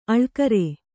♪ aḷkare